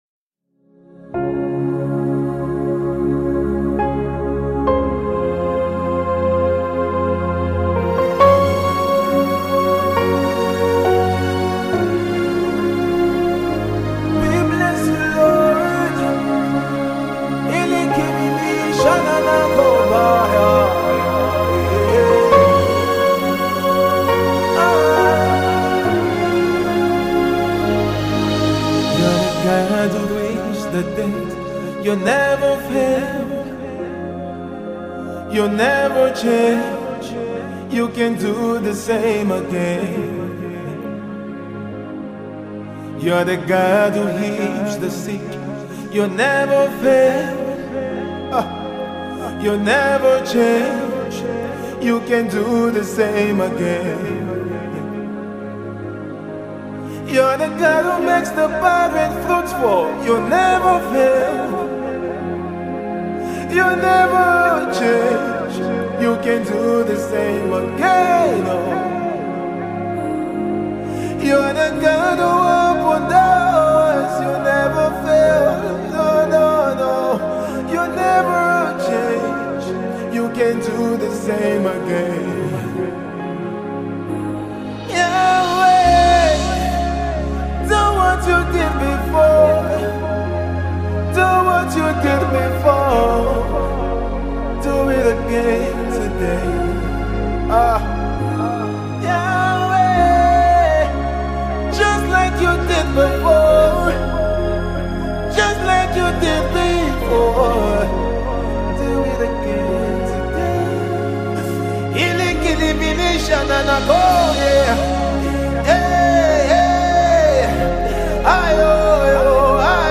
soul-stirring worship song
gospel singer